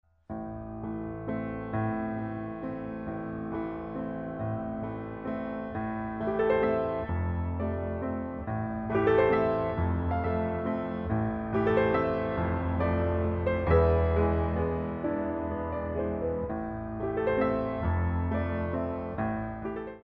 4 Count introduction included for all selections
3/4 - 128 with repeat